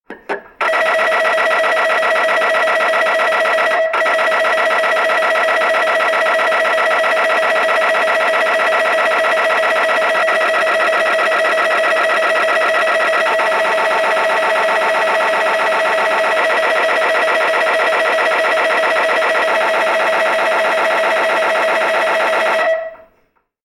На этой странице представлены звуки работы аппарата МРТ – от ритмичных постукиваний до гудения разной интенсивности.
Шум работы томографа при сканировании участков тела